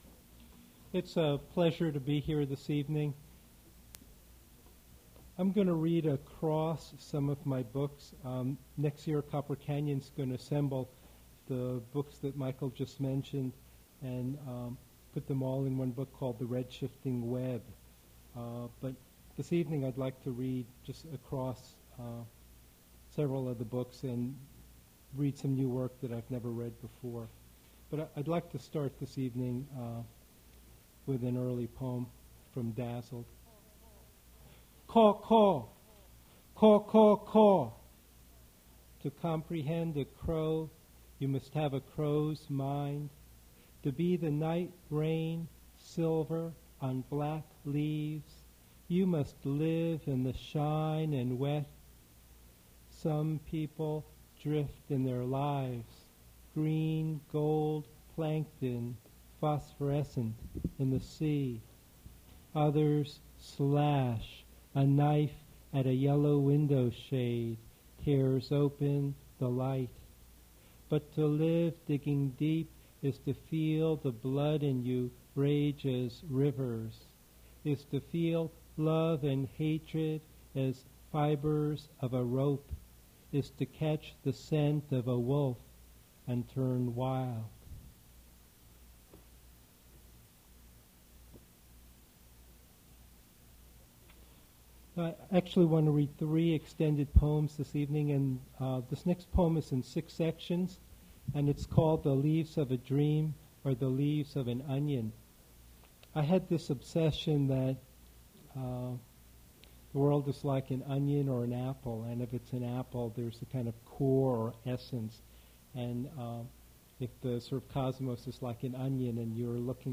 Poetry reading featuring Arthur Sze
Attributes Attribute Name Values Description Arthur Sze poetry reading at Duff's Restaurant.
Source mp3 edited access file was created from unedited access file which was sourced from preservation WAV file that was generated from original audio cassette. Language English Identifier CASS.780 Series River Styx at Duff's River Styx Archive (MSS127), 1973-2001 Note Sze states that Archipelago is split into 9 sections.